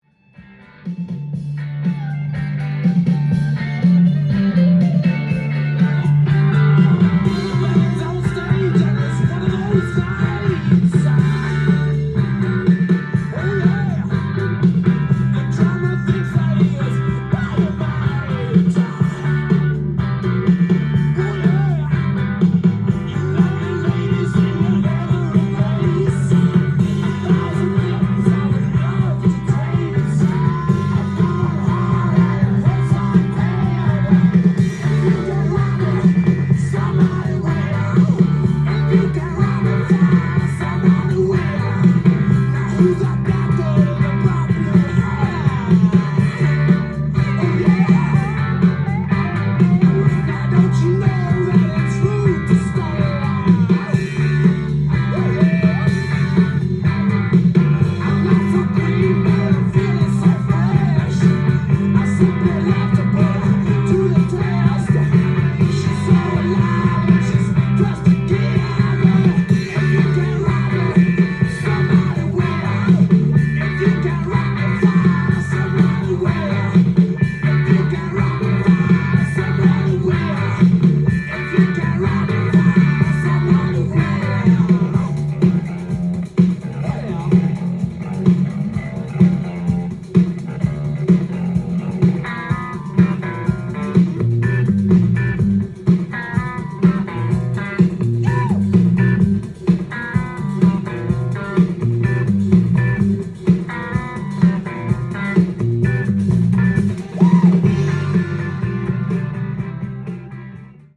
ジャンル：ROCK & POPS
店頭で録音した音源の為、多少の外部音や音質の悪さはございますが、サンプルとしてご視聴ください。